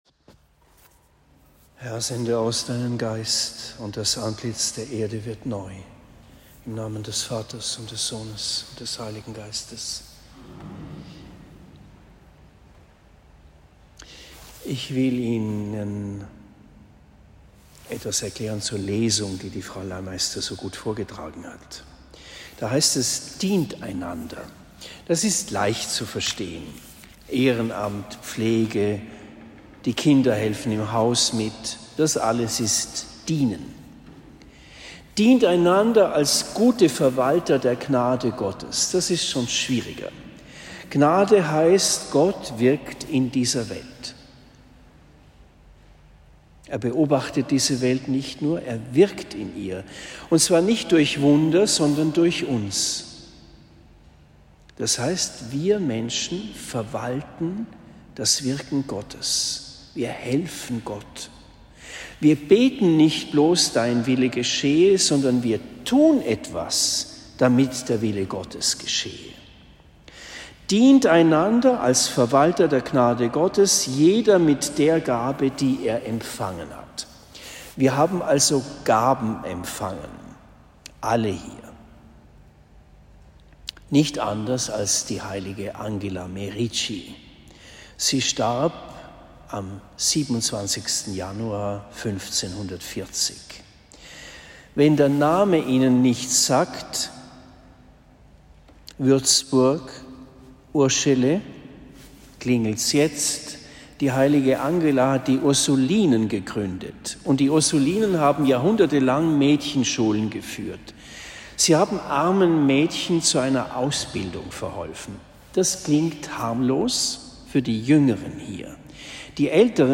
Predigt in Oberndorf im Spessart